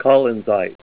Help on Name Pronunciation: Name Pronunciation: Collinsite + Pronunciation
Say COLLINSITE Help on Synonym: Synonym: ICSD 4258   PDF 26-1063